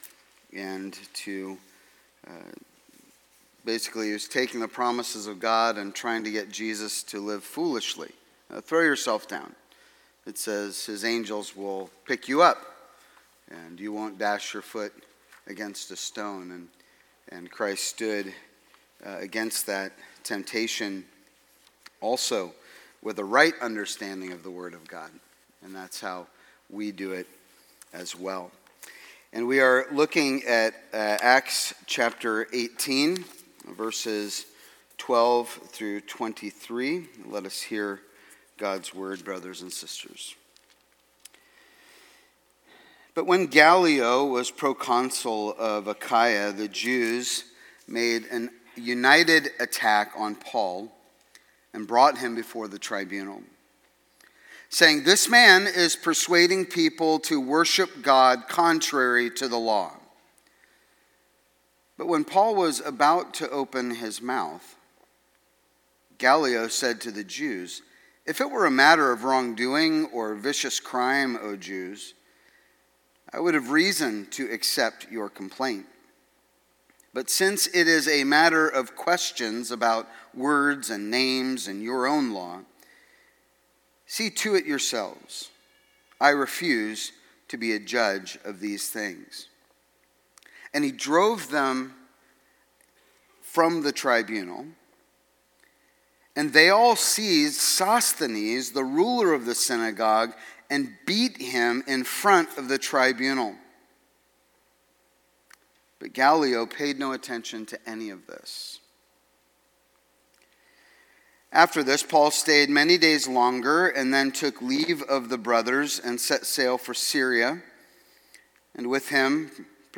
Sermons | New Life Presbyterian Church of La Mesa